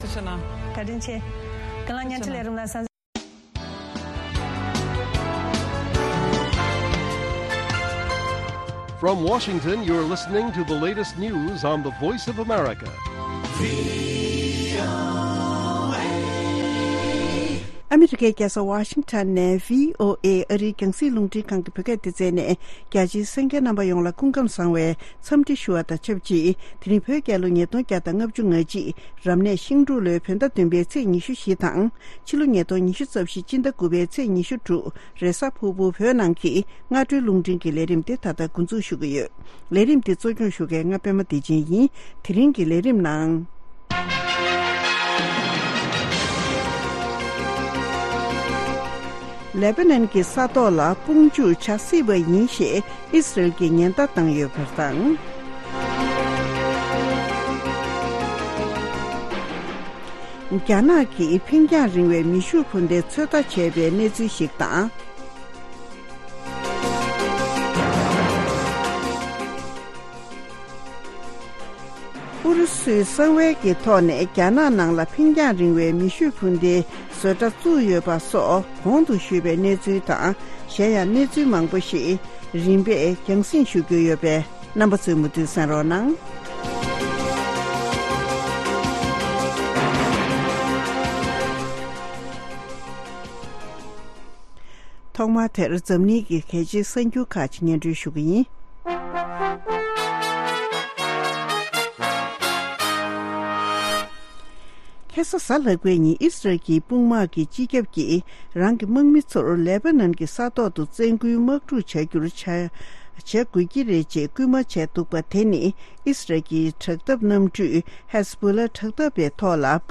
སྔ་དྲོའི་རླུང་འཕྲིན།